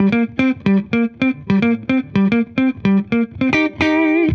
Index of /musicradar/dusty-funk-samples/Guitar/110bpm
DF_70sStrat_110-C.wav